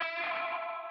Vanilla Sky Vox.wav